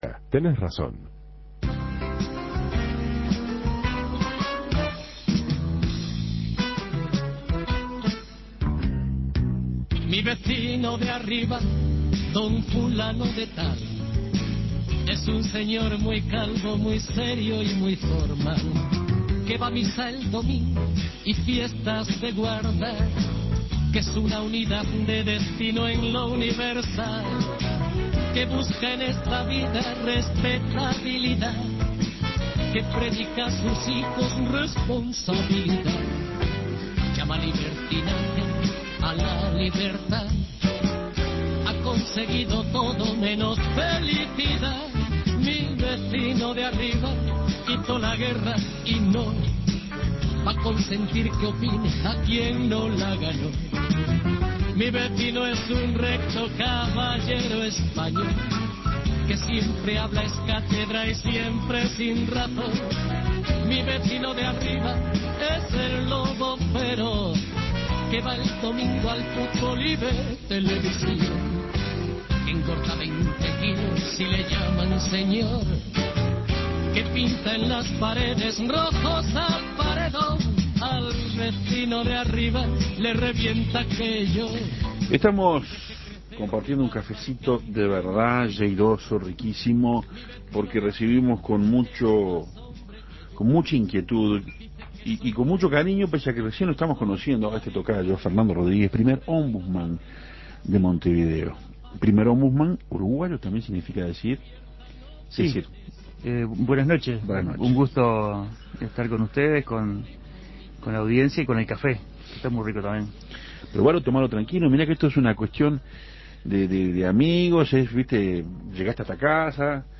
El ombudsman y psicólogo social Fernando Rodríguez estuvo en Café Torrado recibiendo las quejas de varios oyentes.